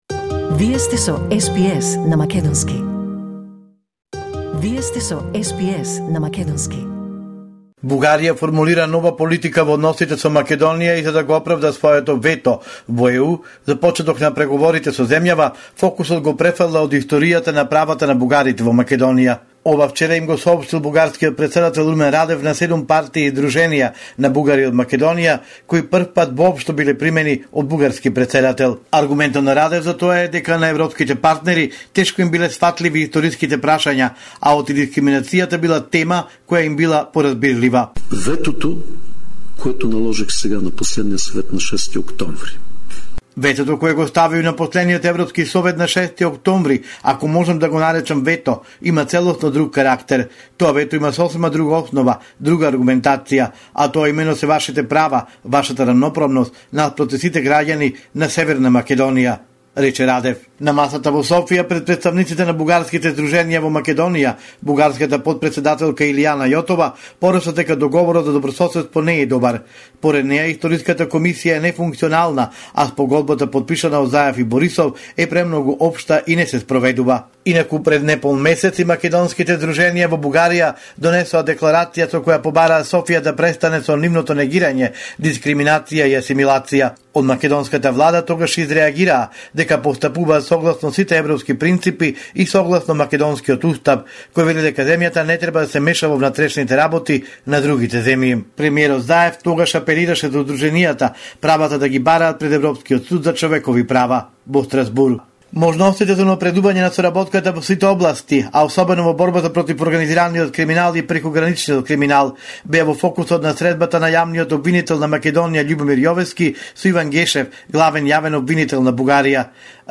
Homeland Report in Macedonian 28 October 2021